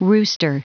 Prononciation du mot rooster en anglais (fichier audio)
Prononciation du mot : rooster